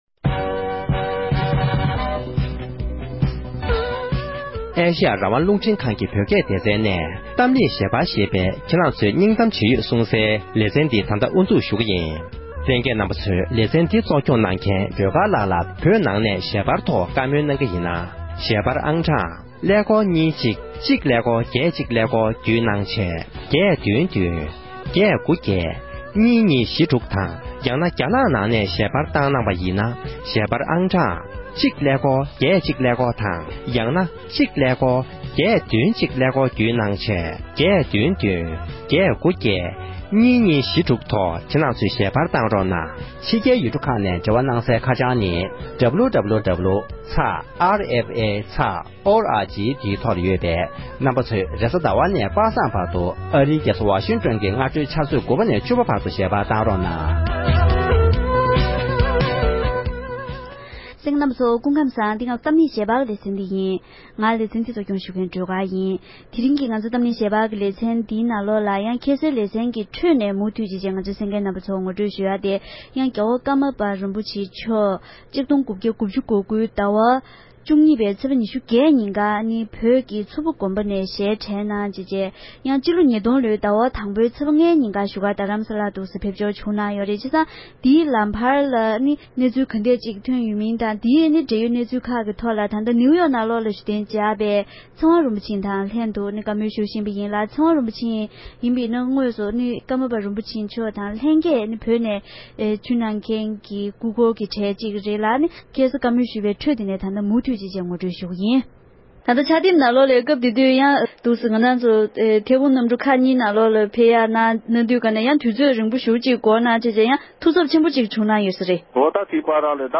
ཀརྨ་པ་རིན་པོ་ཆེའི་ལྷན་དུ་བཙན་བྱོལ་དུ་ཕེབས་མཁན་སྐུ་འཁོར་ཞིག་གི་ལྷན་དུ་བཀའ་མོལ་ཞུས་པ།